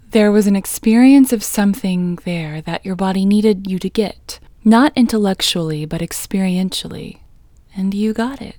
IN Technique First Way – Female English 30